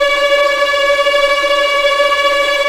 Index of /90_sSampleCDs/Roland L-CD702/VOL-1/STR_Vlns Tremelo/STR_Vls Trem wh%